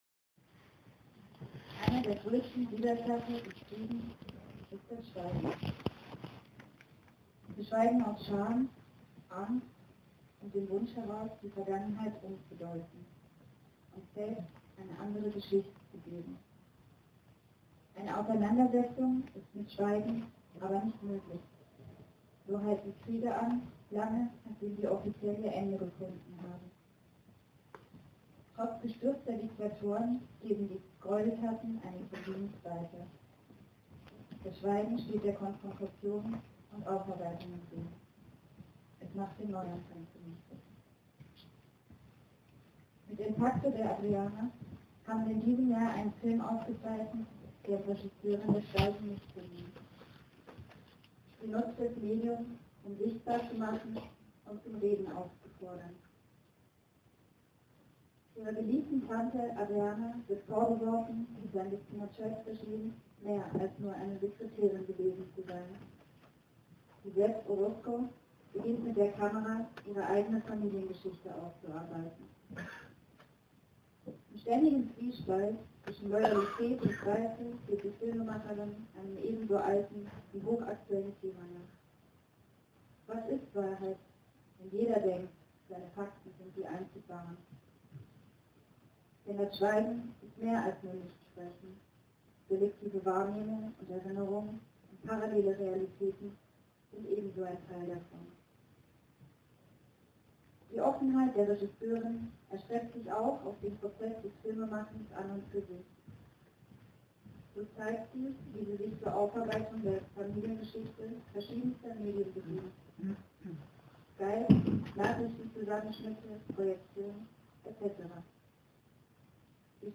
Heute Abend durfte ich der Verleihung des 32.Friedensfilmpreises beiwohnen.